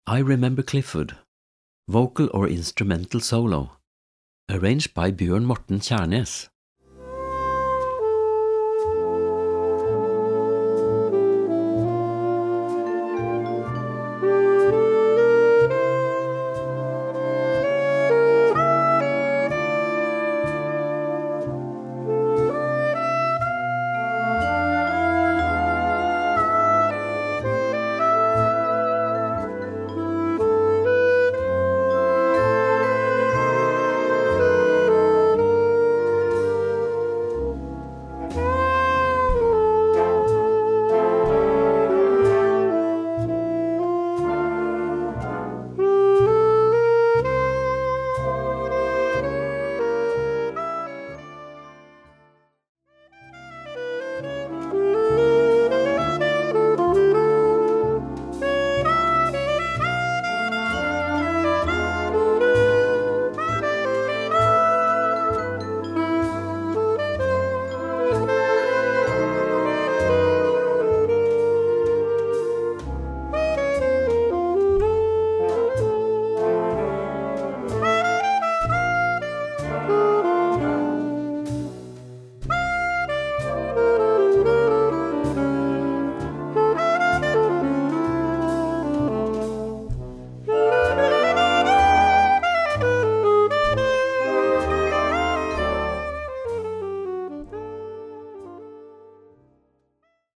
Ensemble 6-7-8 voix Flex